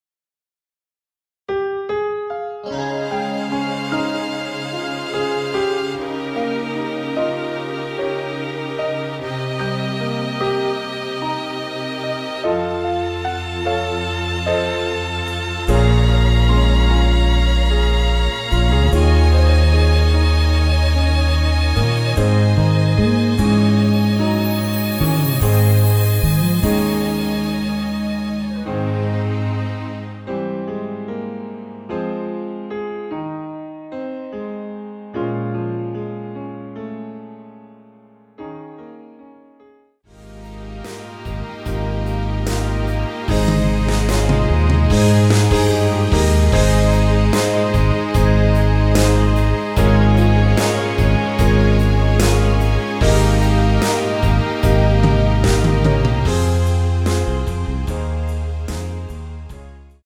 원키에서(+1)올린 MR입니다.
Ab
앞부분30초, 뒷부분30초씩 편집해서 올려 드리고 있습니다.
중간에 음이 끈어지고 다시 나오는 이유는